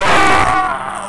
death4.wav